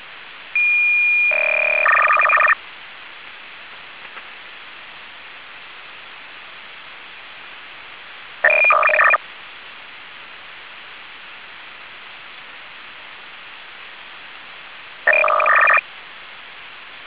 ALCATEL 801H 4-FSK audio samples
Alcatel 801H 4-tone MFSK system running 150 Bd / 300 bps (ISS mode) Alcatel 801H 4-tone MFSK system running 600 Bd / 1200 bps, 300 Bd / 600 bps and 150 Bd / 300 bps (ISS mode) Alcatel 801H 4-tone MFSK system running 150 Bd / 300 bps (IRS mode)